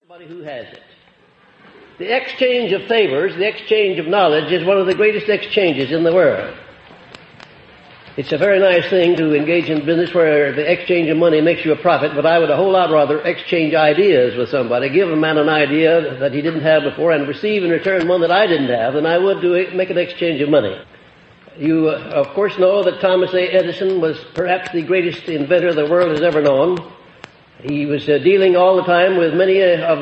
The lost recordings of Napoleon Hill are lectures as he personally promoted his philosophy of wealth and achievement, as written about in his famous books Law of Success and Think and Grow Rich.
Napoleon_Hill_Rare_Recordings_02.mp3